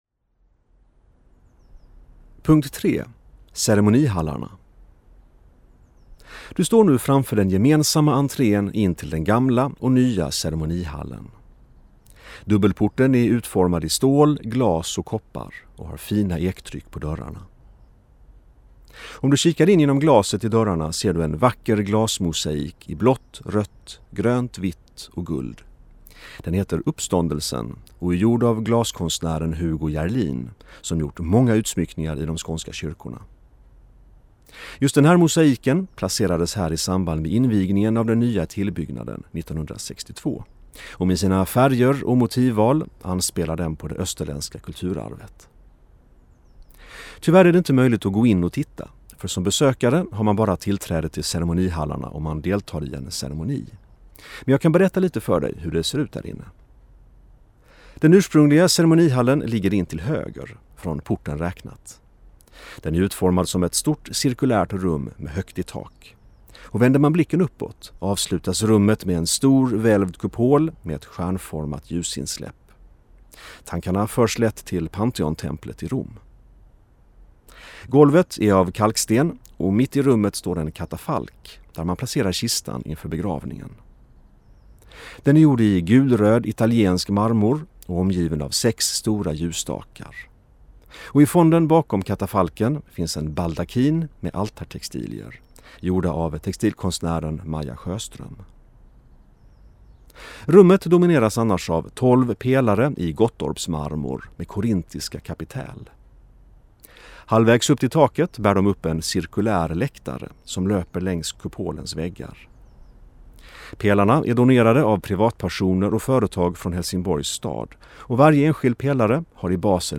Ljudguidad rundtur